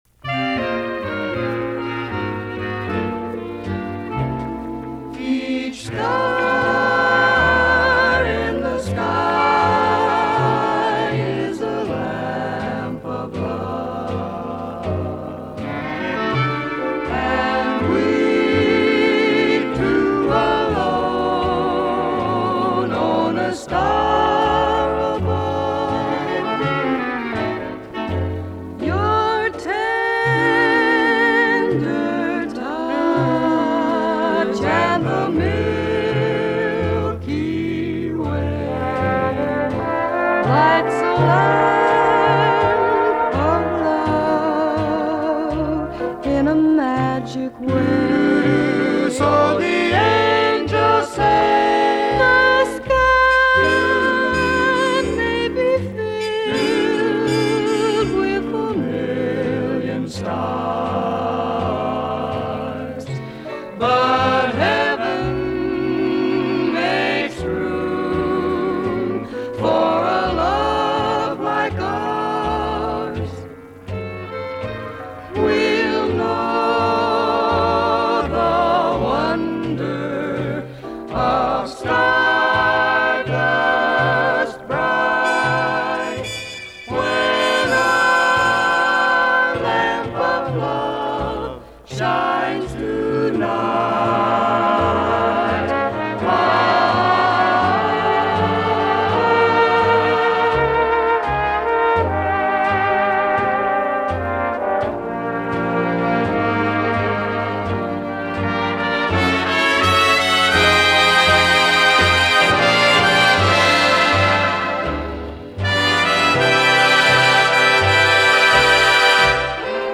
It’s light, listenable and non-threatening.